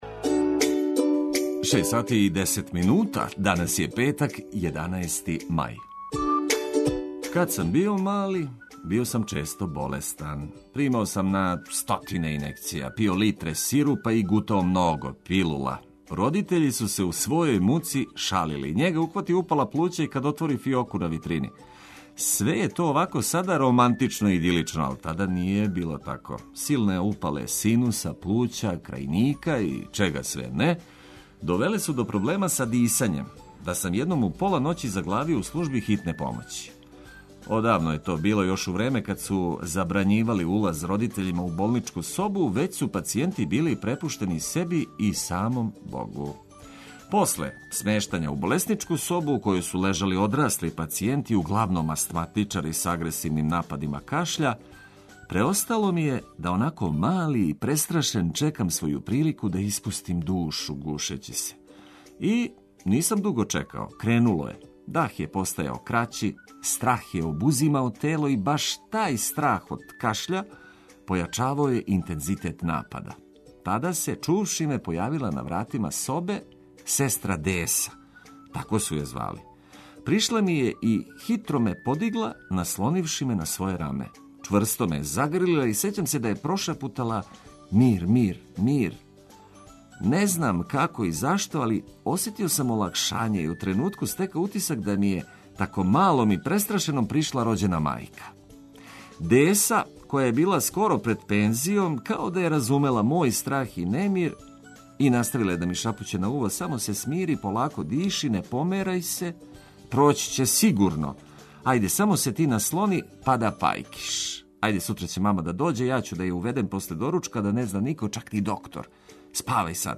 Уз хитове за сва времена и важне информације разбудите се у нашем друштву.